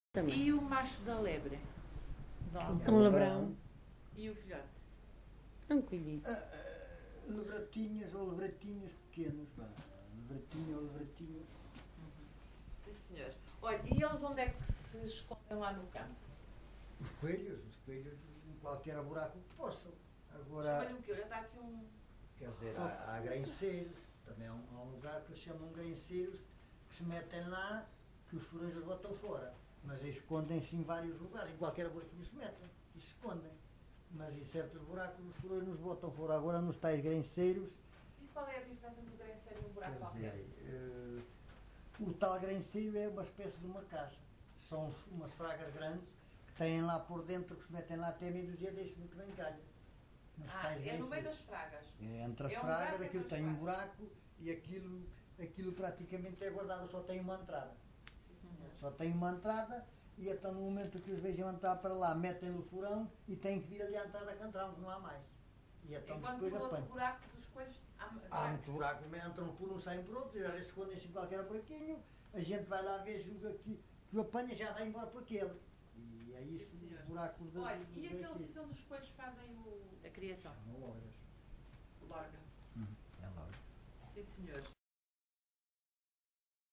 LocalidadeLarinho (Torre de Moncorvo, Bragança)